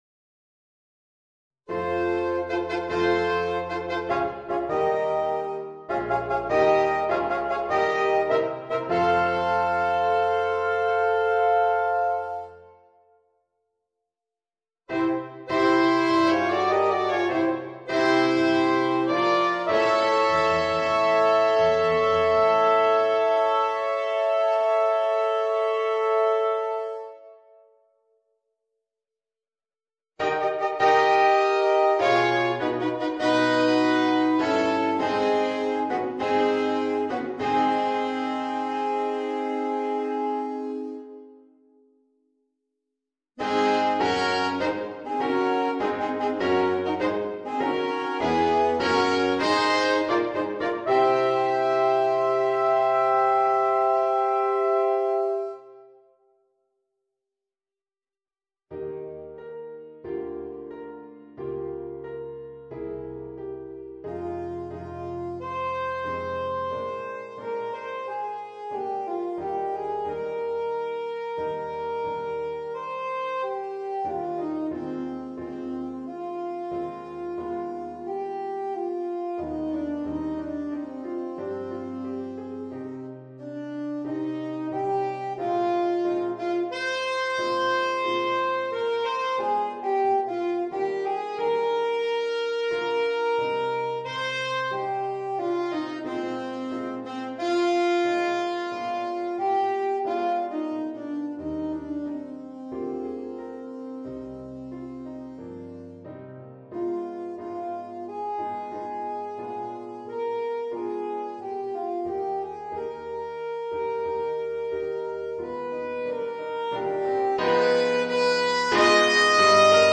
Saxophone Alto et Piano ou Orgue